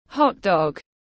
Bánh mì kẹp xúc xích tiếng anh gọi là hotdog, phiên âm tiếng anh đọc là /ˈhɒt.dɒɡ/
Hotdog /ˈhɒt.dɒɡ/